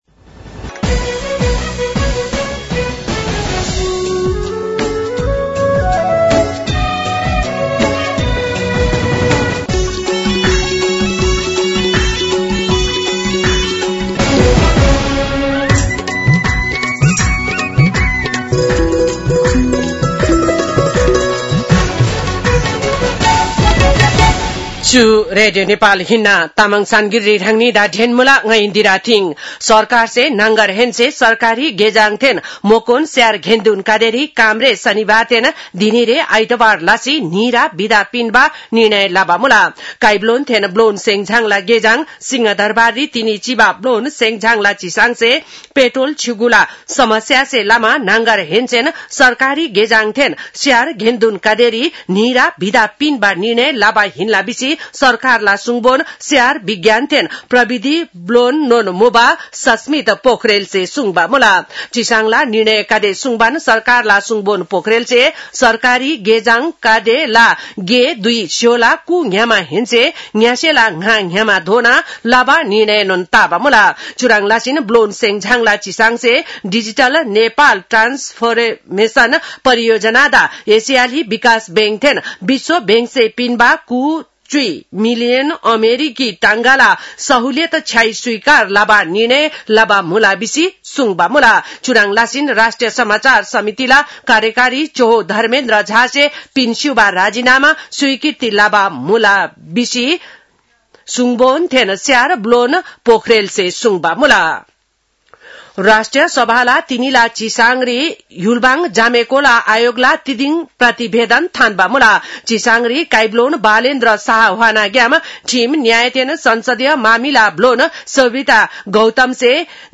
तामाङ भाषाको समाचार : २२ चैत , २०८२